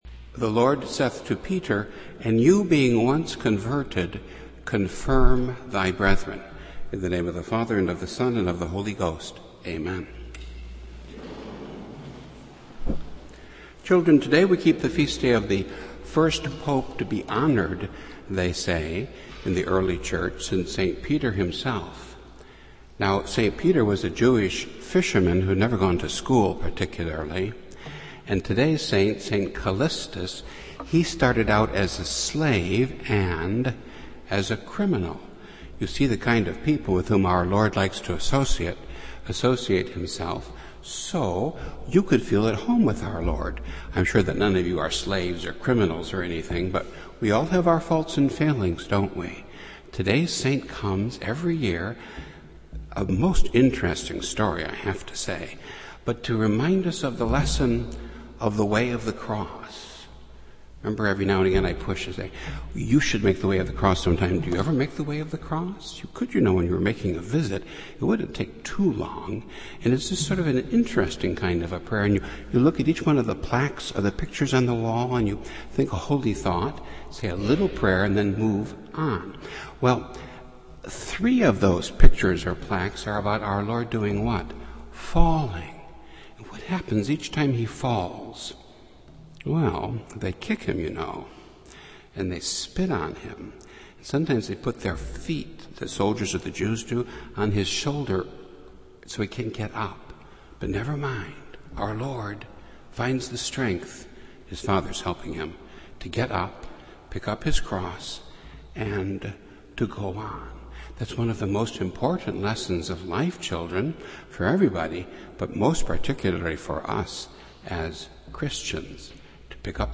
Daily Sermons